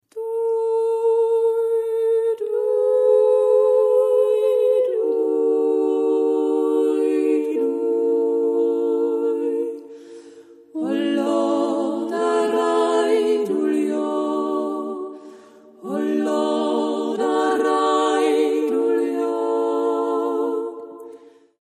Musik aus dem Mostviertel
Aufgenommen im Tonstudio